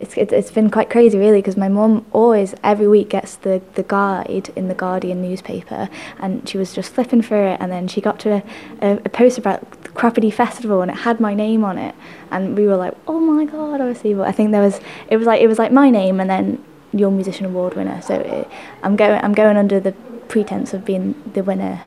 She told Manx Radio the tour has very much been sparked by that accolade: Listen to this audio